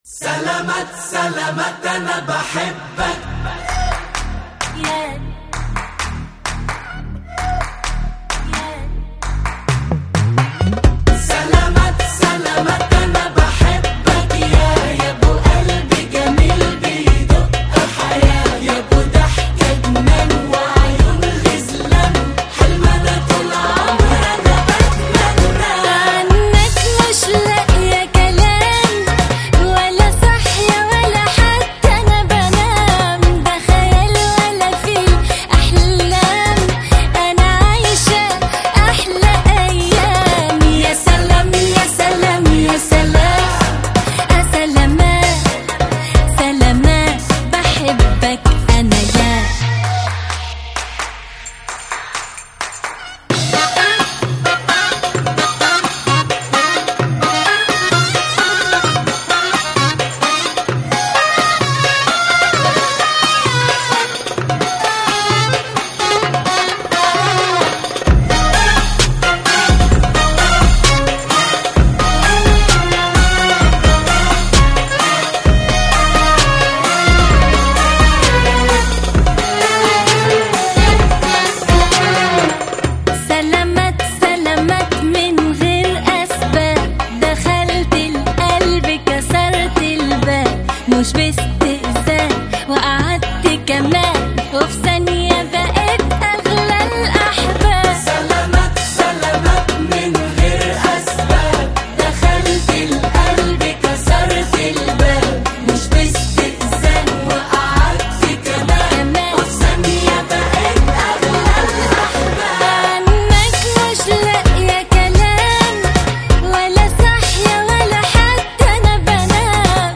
Жанр: Arabic Pop